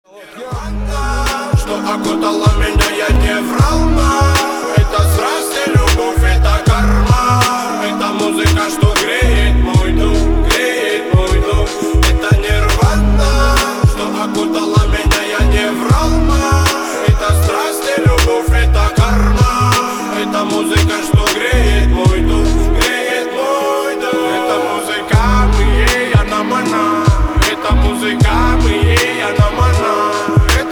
• Качество: 320, Stereo
мужской вокал
рэп
Хип-хоп
припев